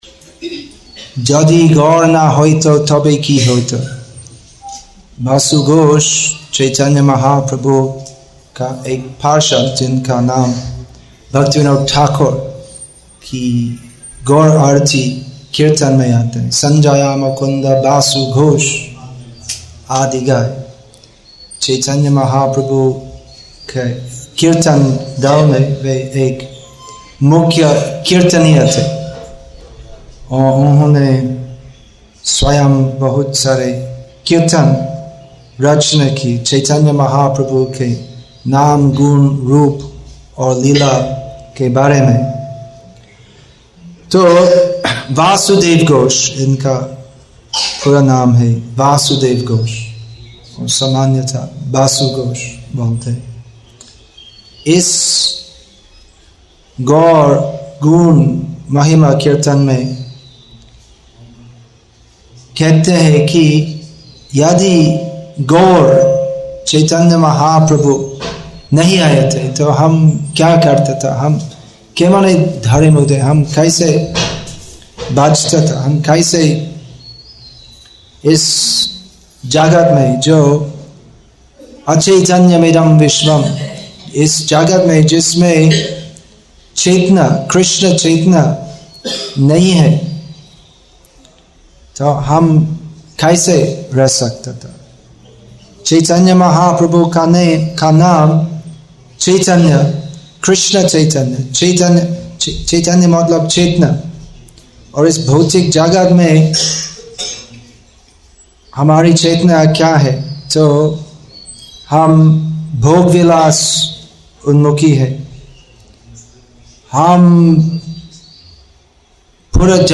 Assorted Lectures